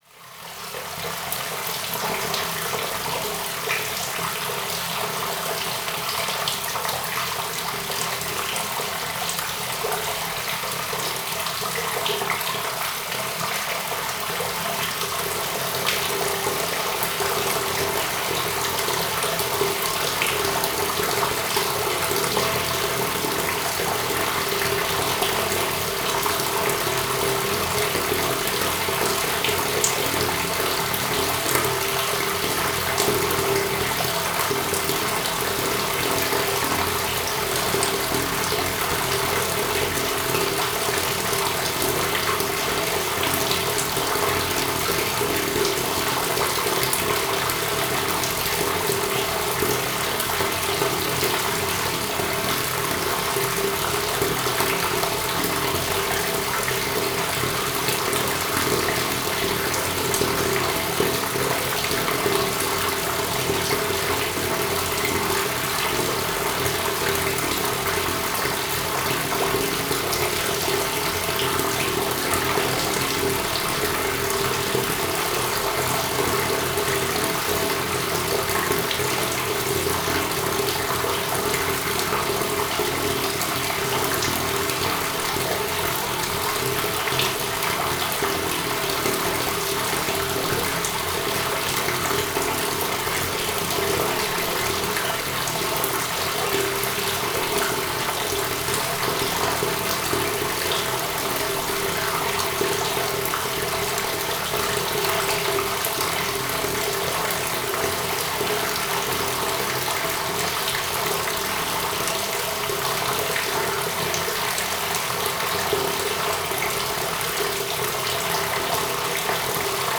Blackwater
aigues-negres.wav